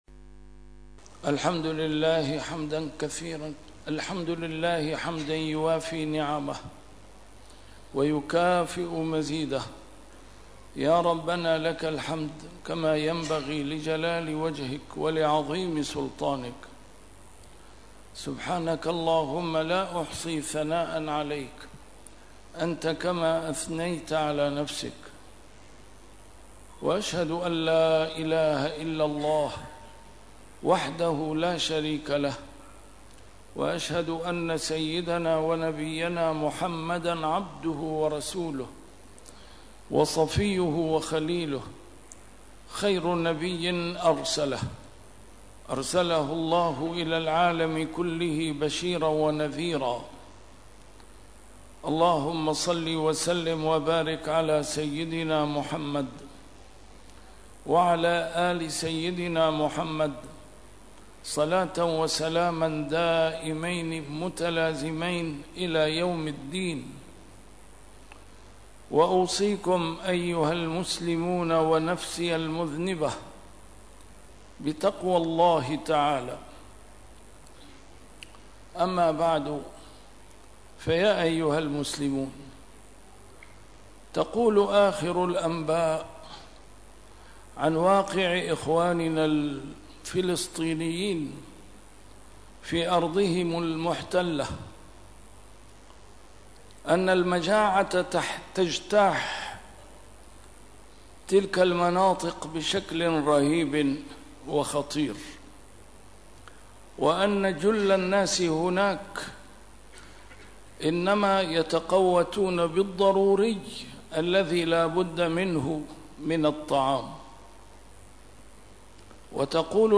A MARTYR SCHOLAR: IMAM MUHAMMAD SAEED RAMADAN AL-BOUTI - الخطب - إخواننا في فلسطين يتضورون جوعاً ومترفوا العرب من حولهم ينعمون بالرقاد